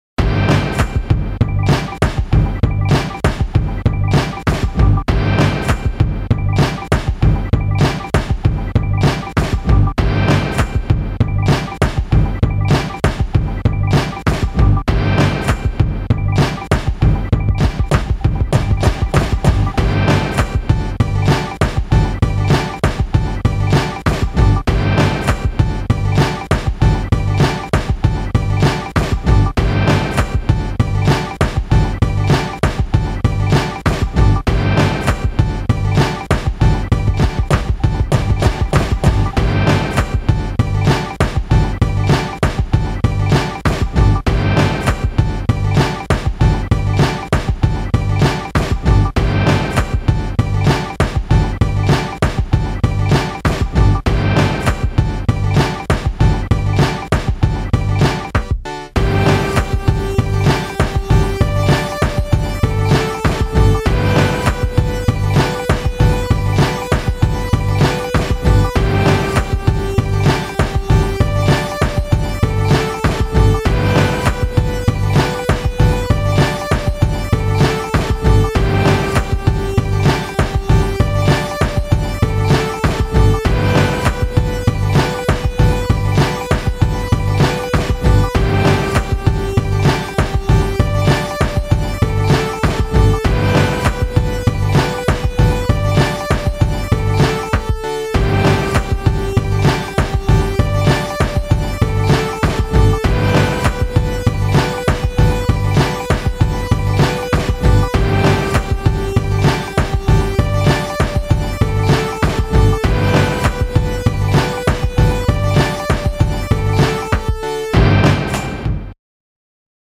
This beat was originally just an exercise with side chain compression, using Reason 4.Â  It samples the Microphones on the drum track, and also some Kraftwerk keyboards.
This is such a sick beat.
What is the little marimba sounding noise?
Nice clatter and swoosh.